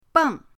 beng4.mp3